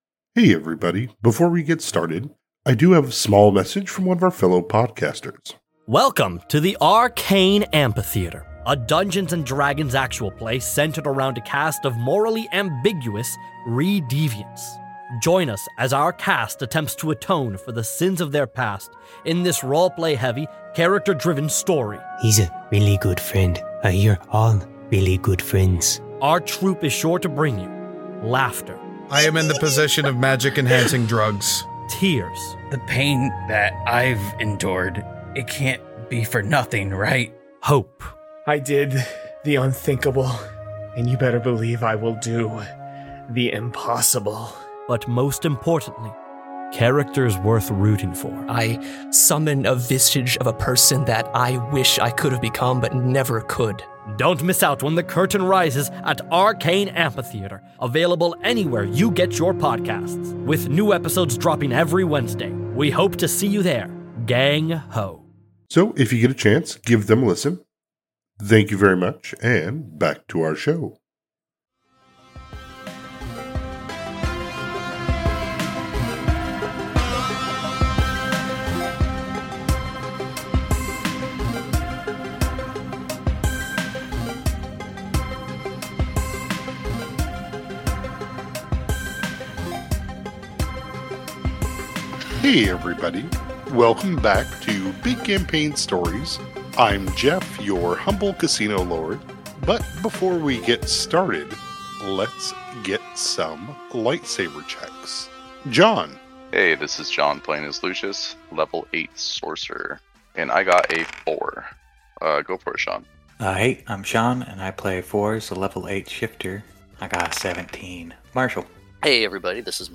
An actual play pathfinder 1e podcast about 5 people running a repo company trying to survive in a modern homebrew setting with magic.RSSVERIFY